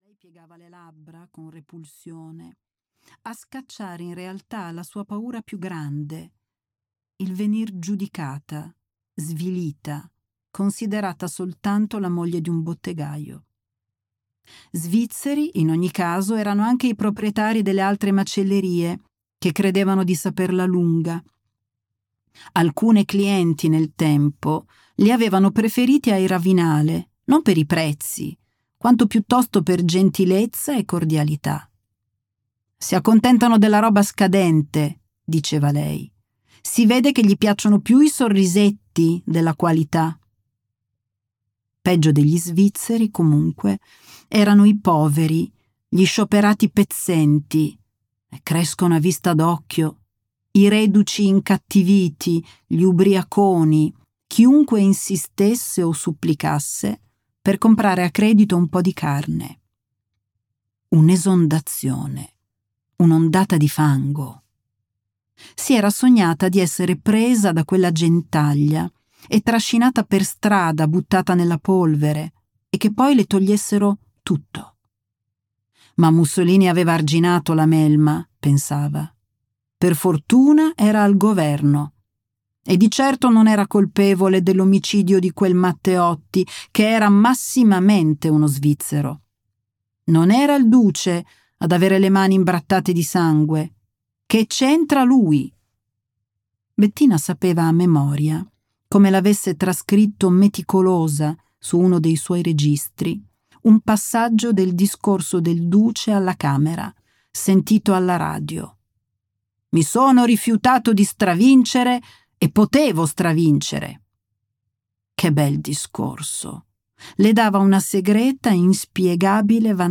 Audiolibro digitale